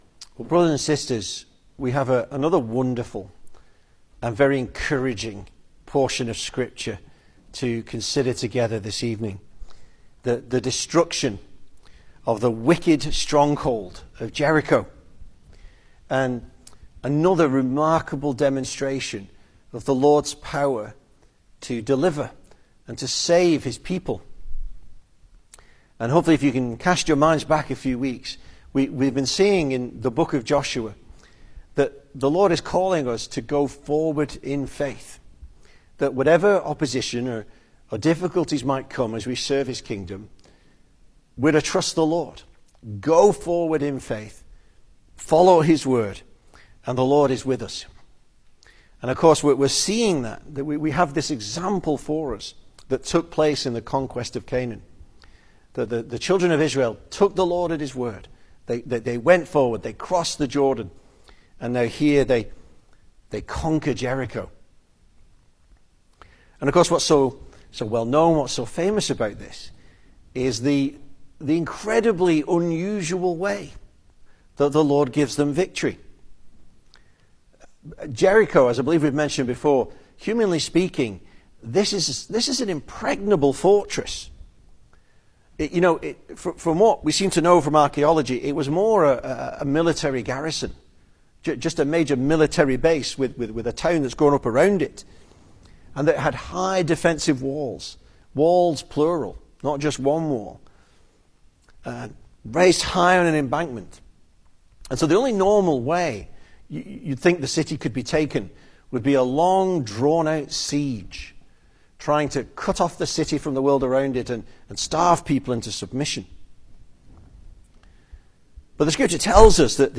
2025 Service Type: Sunday Evening Speaker